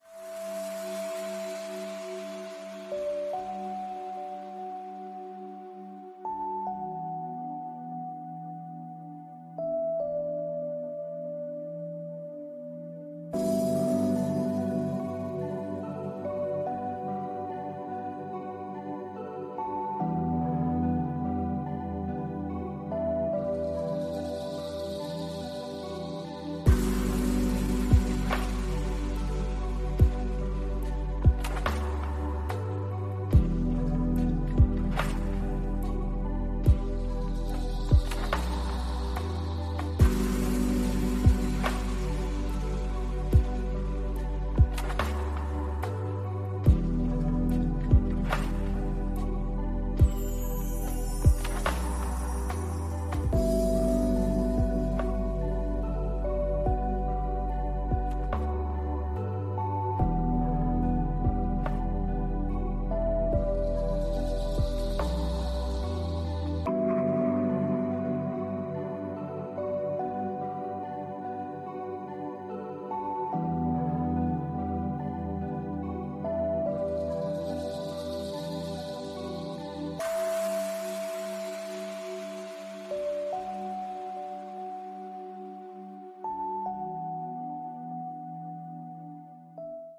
Ambient_0323_5_fade.mp3